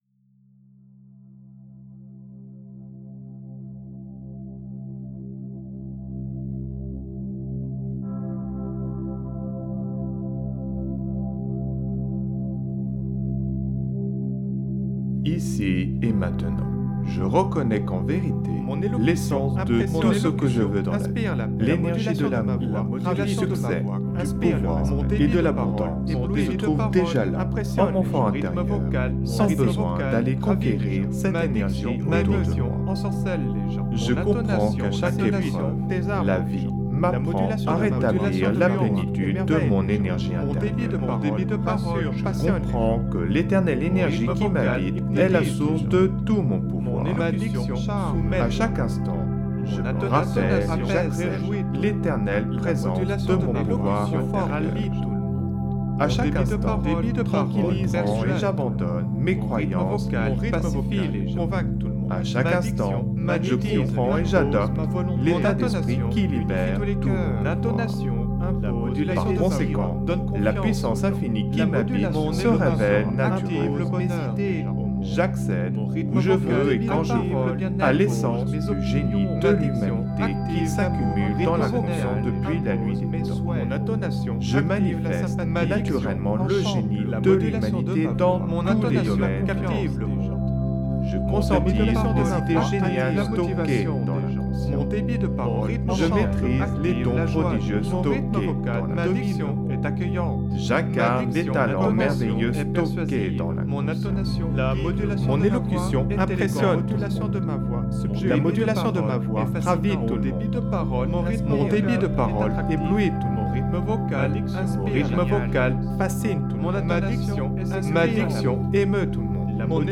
Son binaural, musicothérapie, message subliminal, méditation, auto hypnose mp3, PNL